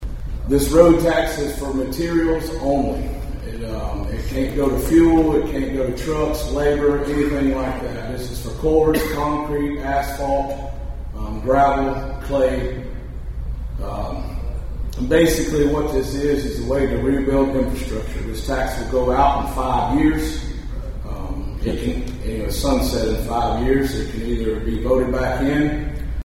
A Countywide Town Hall Meeting was held last night at the Thayer High School.